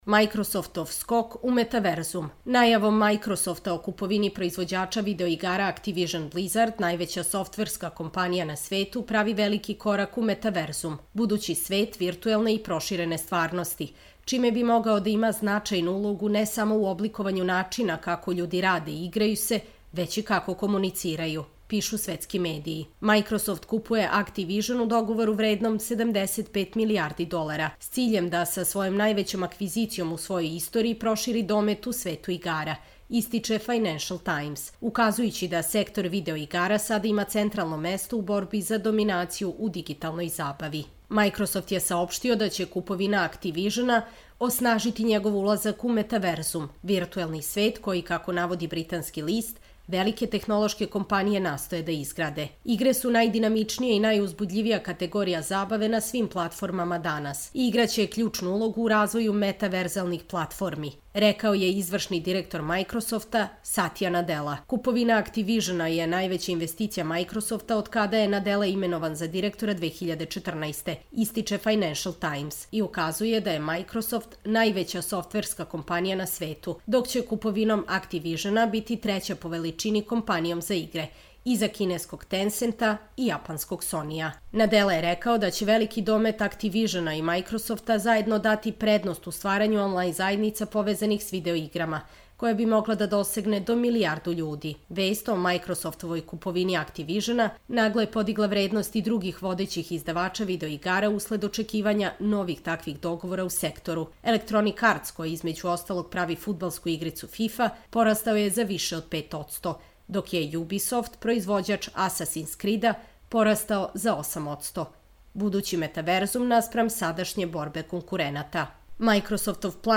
Čitamo vam: Microsoftov skok u metaverzum